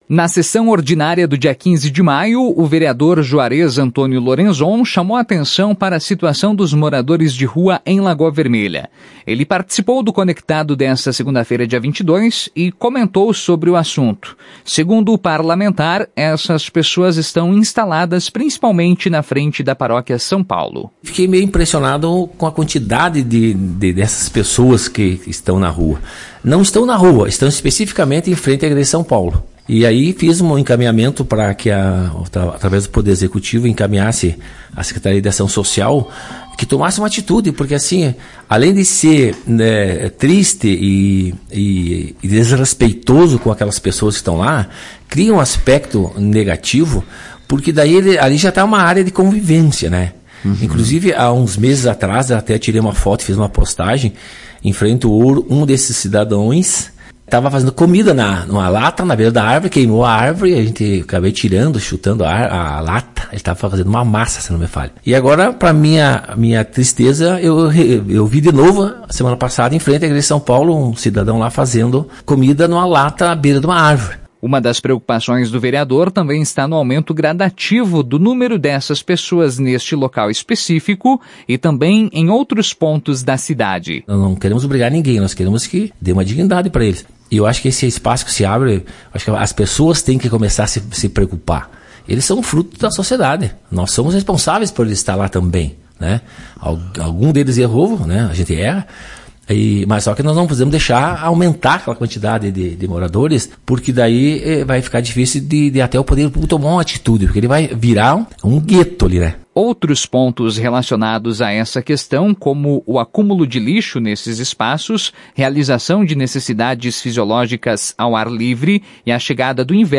Parlamentar participou do Conectado desta segunda-feira, dia 22